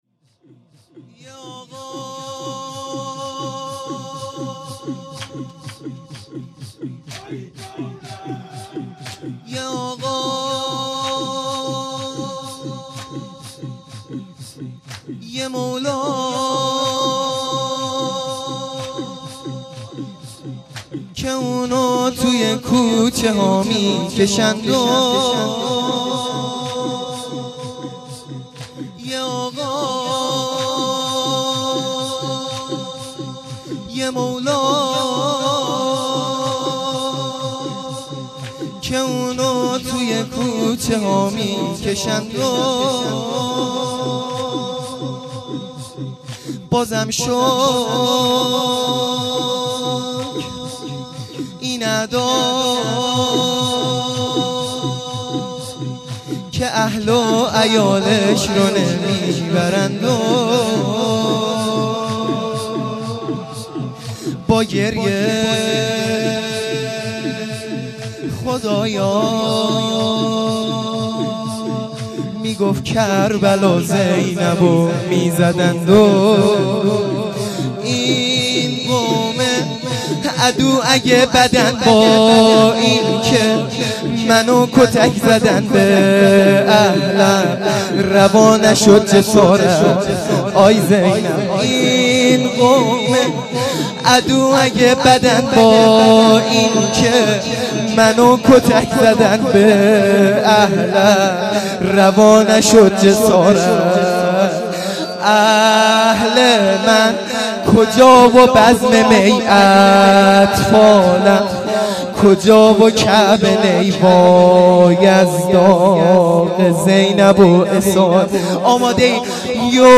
شور | یه آقا یه مولا
شهادت امام صادق علیه السلام